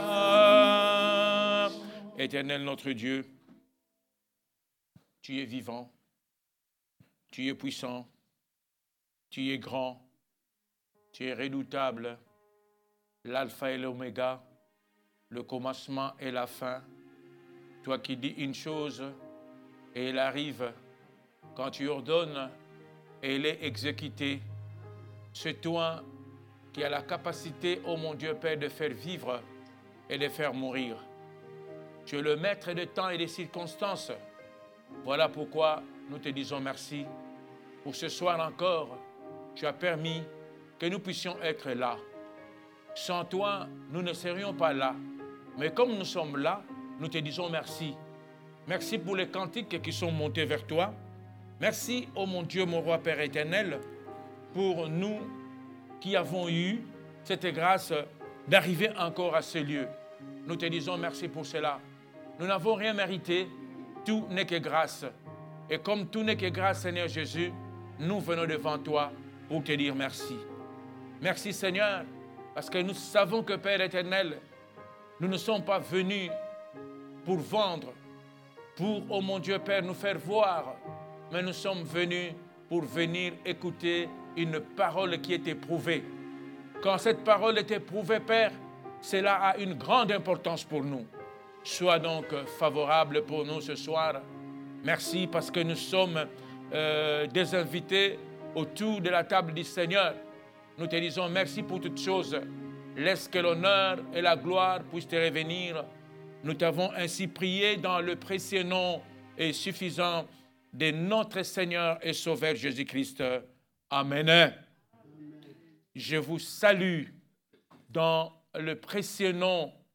Prédications